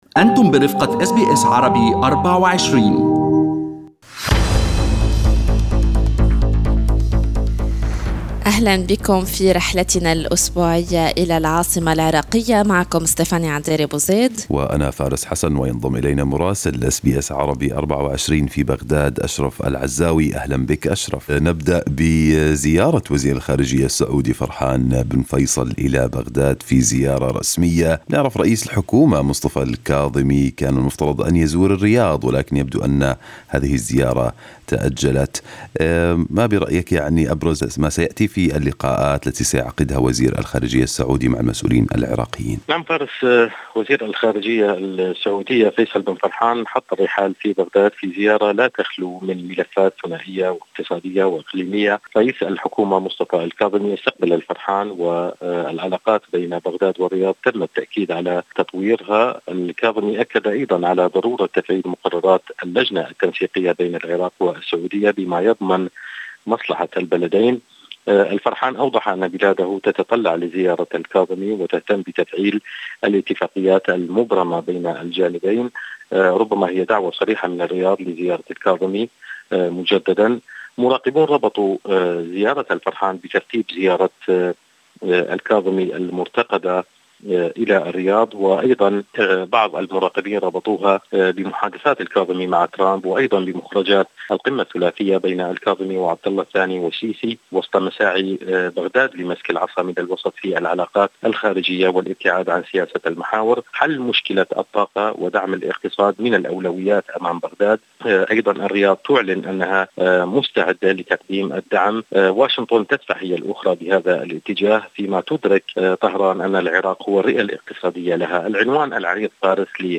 من مراسلينا: أخبار العراق في أسبوع 28/8/2020